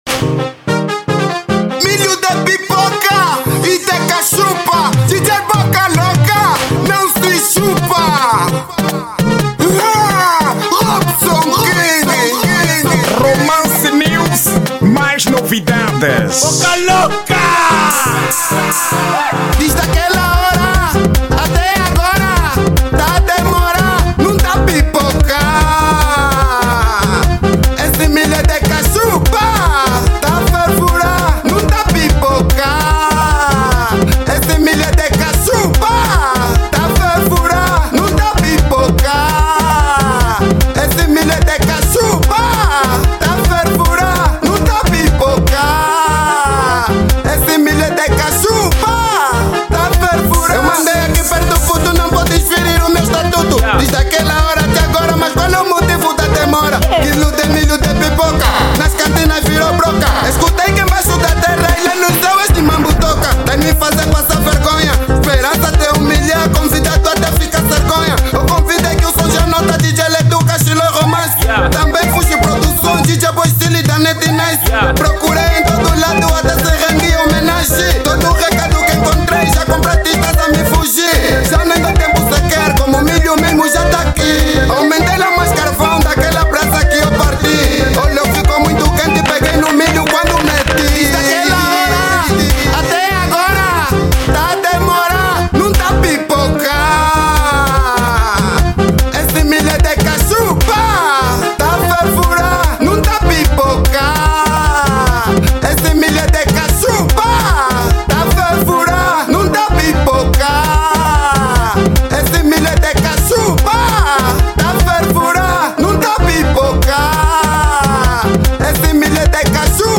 Estilo: Kuduro